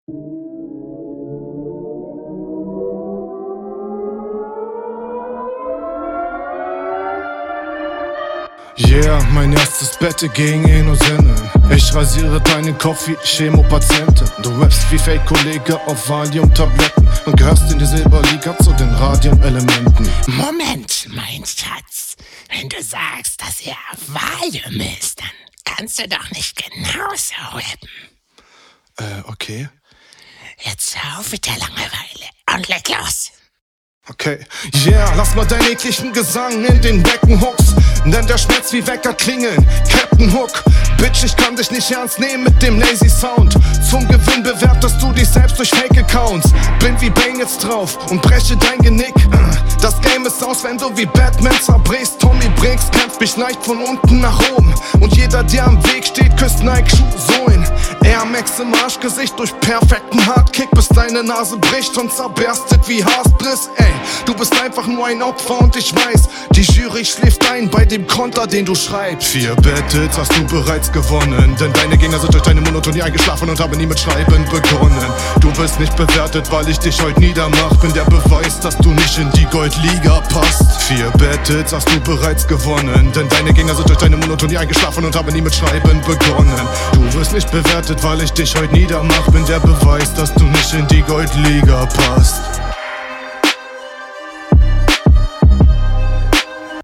die stellen in denen du doubletime rappst hättest du dir sparen können. es gibt in …